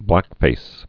(blăkfās)